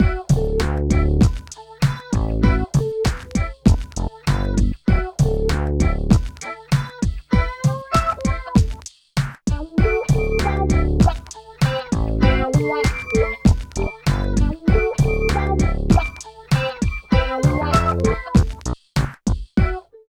71 LOOP   -R.wav